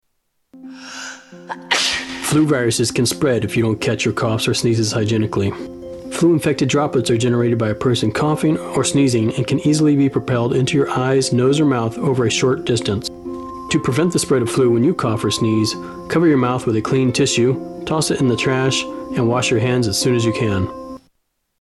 Tags: Media Flu PSA's Flu Public Service Announcements H1N1